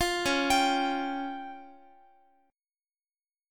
Listen to DbMb5 strummed